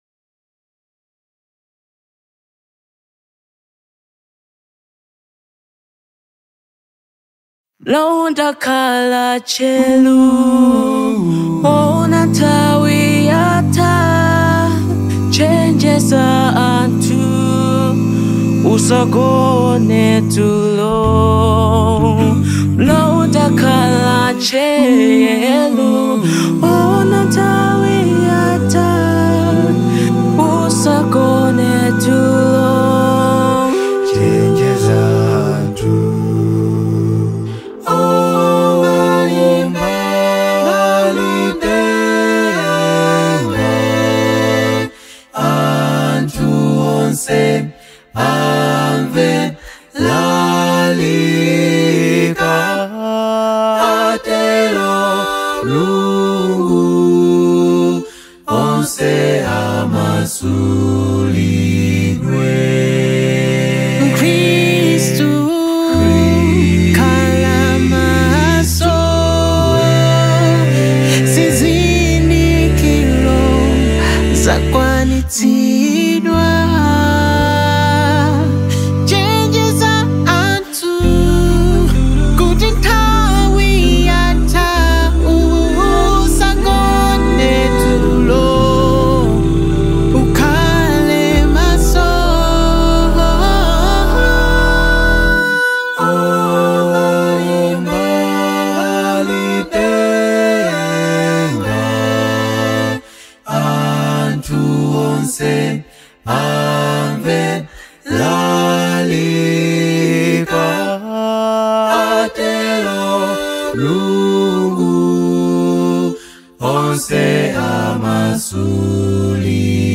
Universal Gospel
The contemporary gospel vocal band